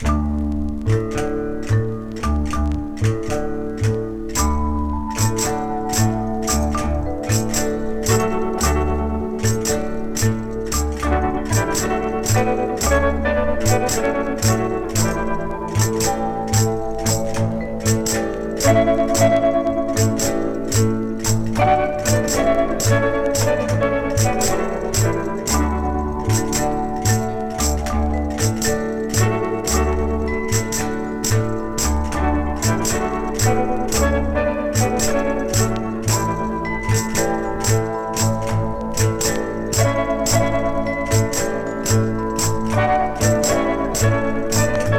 小気味良いアレンジに多種多様な楽器演奏、音の粒立ちの良さと奥行きあるサウンドに耳も心も嬉しくなります。
Jazz, Latin, Easy Listening　USA　12inchレコード　33rpm　Stereo